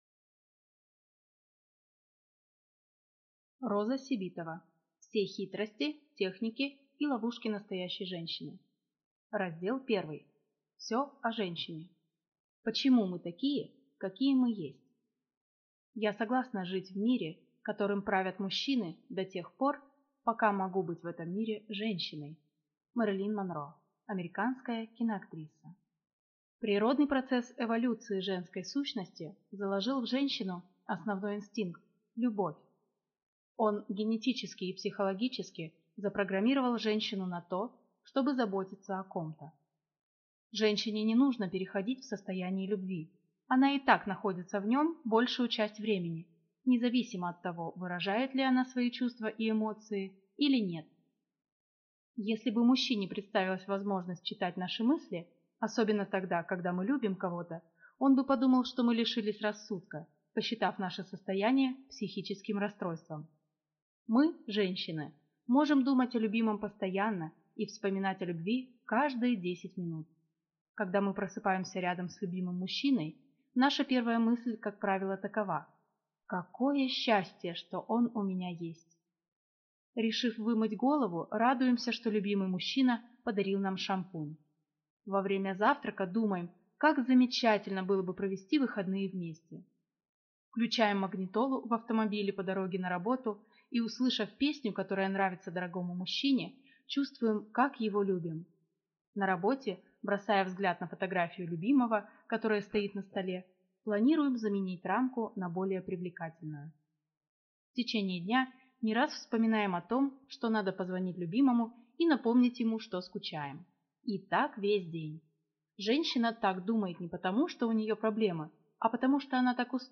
Аудиокнига Все хитрости, техники и ловушки настоящей женщины | Библиотека аудиокниг